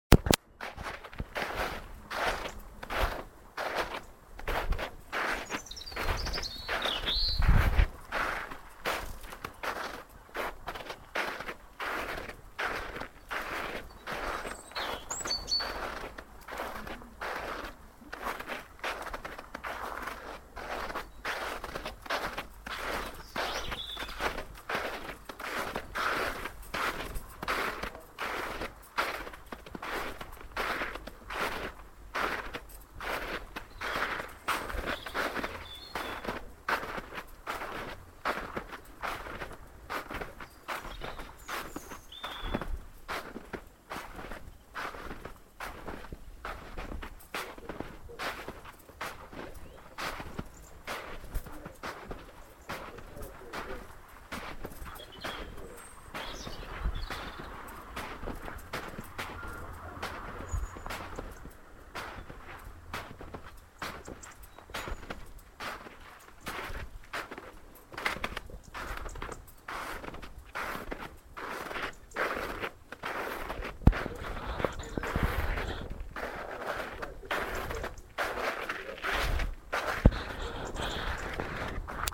Headliner Embed Embed code See more options Share Facebook X Subscribe Share Facebook X Subscribe Next Piano piece recorded in 1999, when I still practised regularly! Bach Sinfonia in A minor